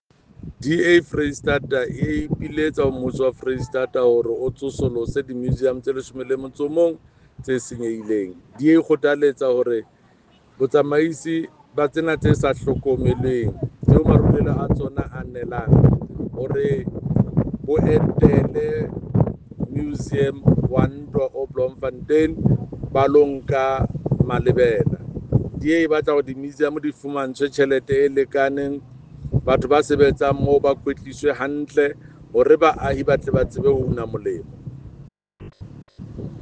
Sesotho soundbite by Jafta Mokoena MPL.